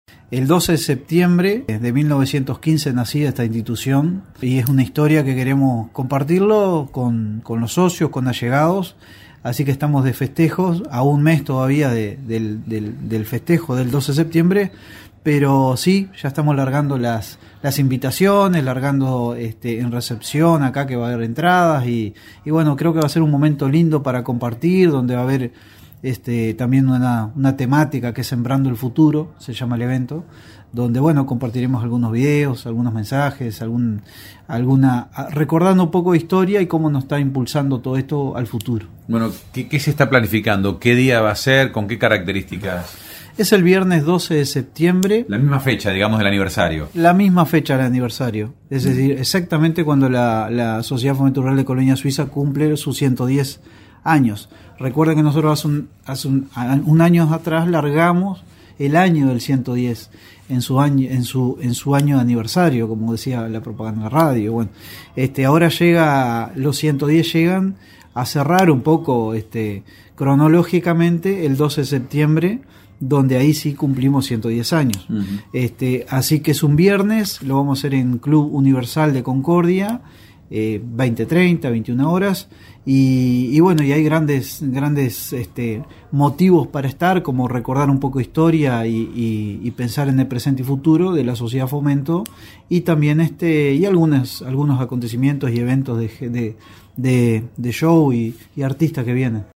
Conversamos